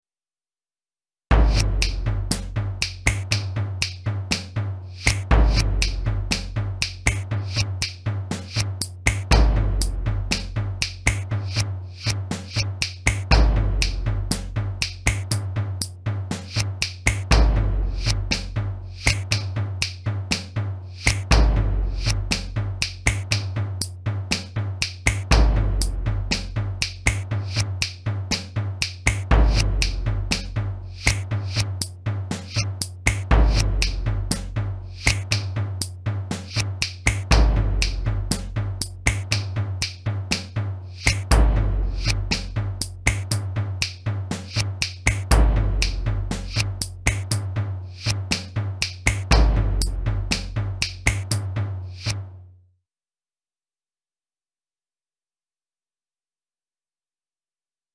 Hier gibt es meine Experimente mit Tönen und Geräuschen sowie diverser Hard- und Software zur Klangerzeugung.
audio-beatmitderfliege.mp3